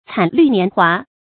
惨绿年华 cǎn lǜ nián huá
惨绿年华发音